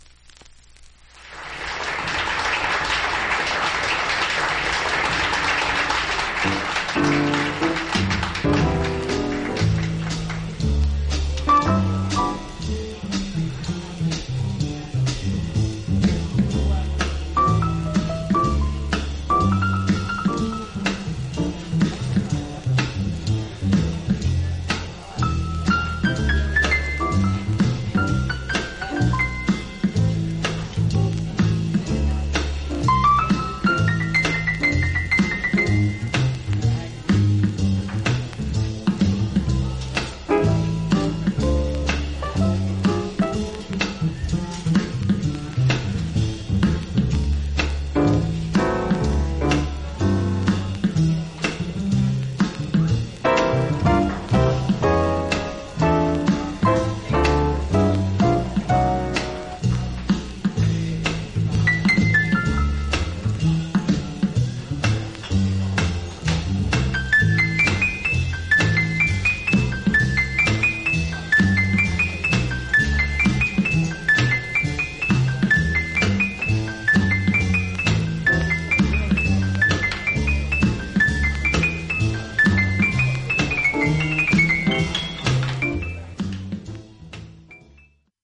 盤面薄いスリキズがそこそこありますが音への影響は少ないと思います。
実際のレコードからのサンプル↓ 試聴はこちら： サンプル≪mp3≫